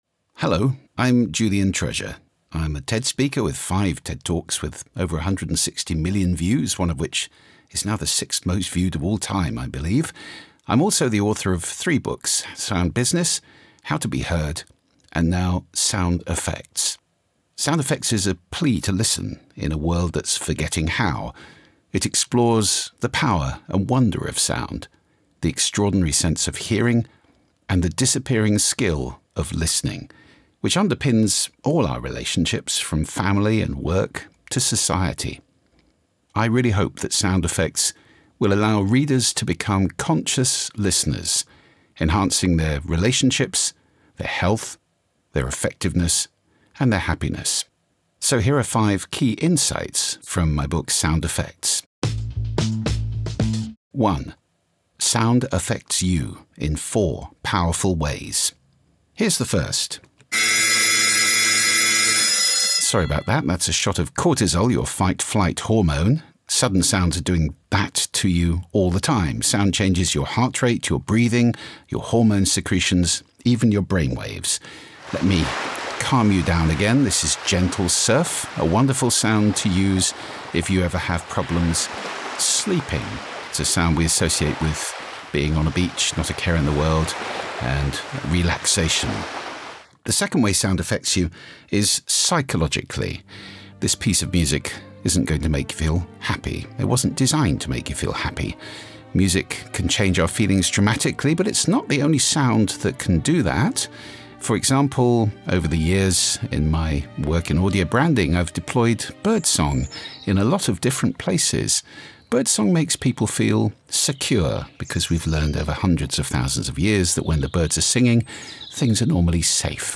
Listen to the audio version—read by Julian himself—below, or in the Next Big Idea App.